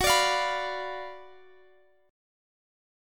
GbM7sus4#5 chord